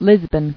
[Lis·bon]